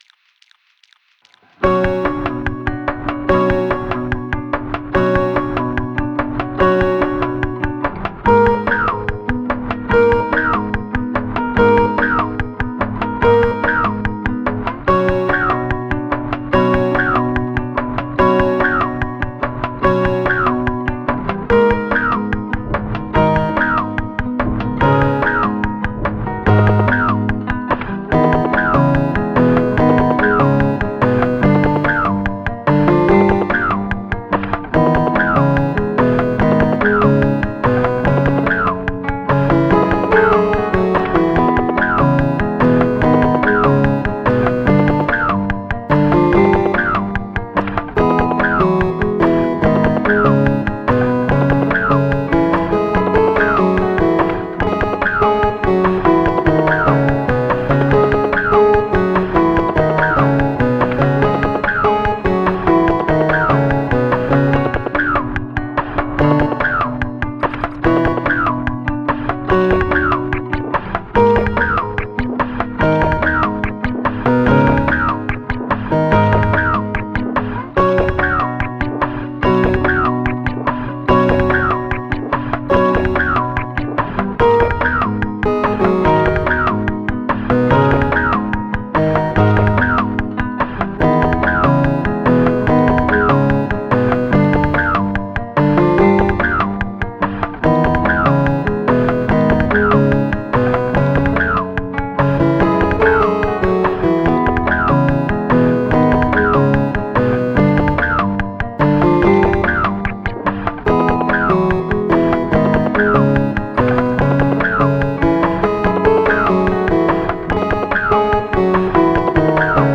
electro-pop
8bit drum kits and droopy synth